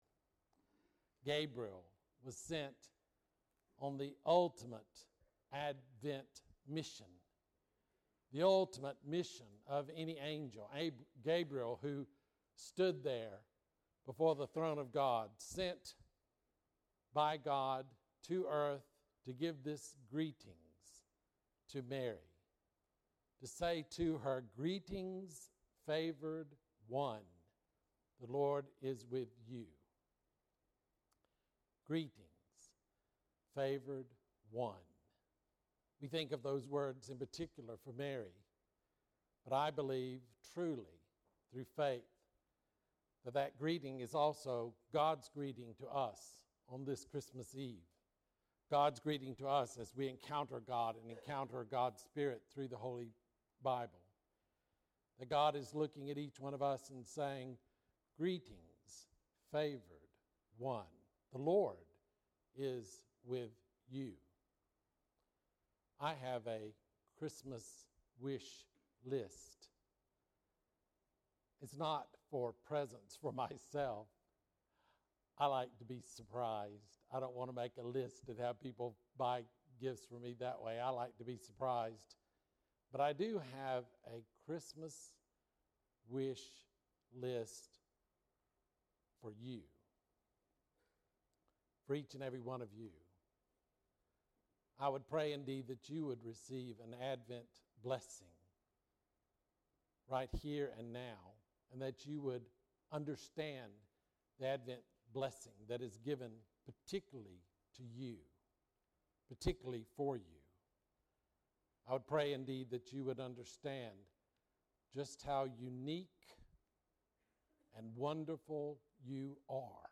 Bible Text: Luke 1:26-38 | Preacher